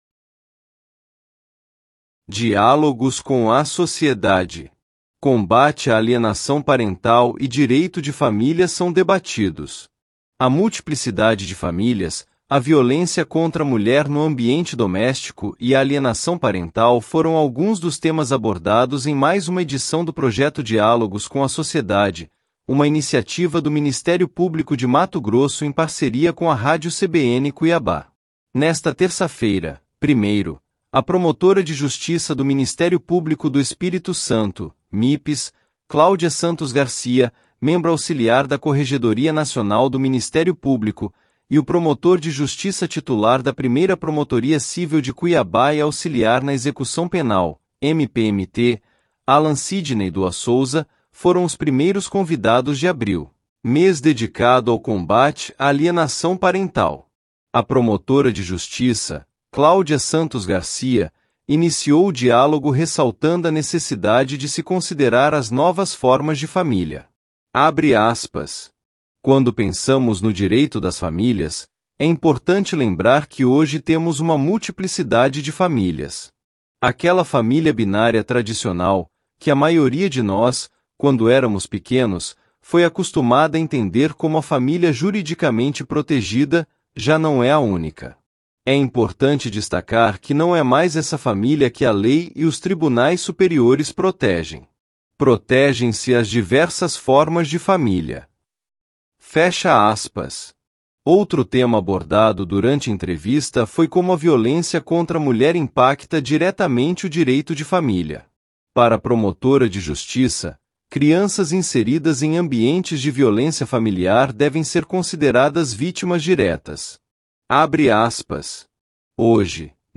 A multiplicidade de famílias, a violência contra a mulher no ambiente doméstico e a alienação parental foram alguns dos temas abordados em mais uma edição do projeto Diálogos com a Sociedade, uma iniciativa do Ministério Público de Mato Grosso em parceria com a Rádio CBN Cuiabá.
As entrevistas do projeto Diálogos com a Sociedade seguem até o dia 11 de abril, das 14h às 15h, no estúdio de vidro localizado na entrada principal do Pantanal Shopping, com transmissão ao vivo pelo canal do MPMT no YouTube.